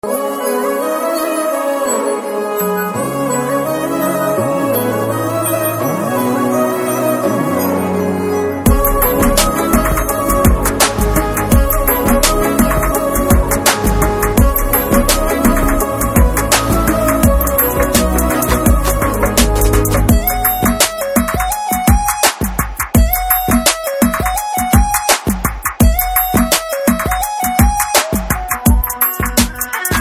best flute ringtone download | dance song ringtone